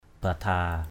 /ba-dha:/